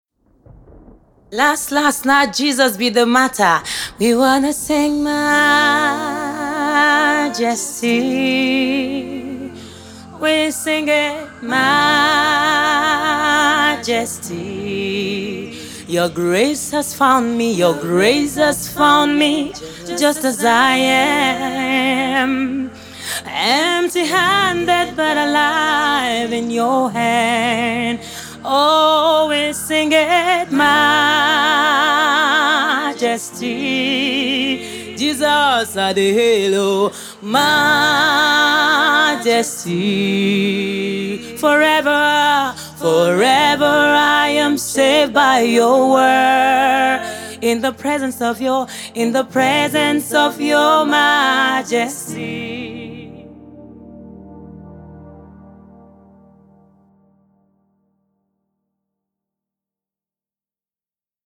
inspirational songs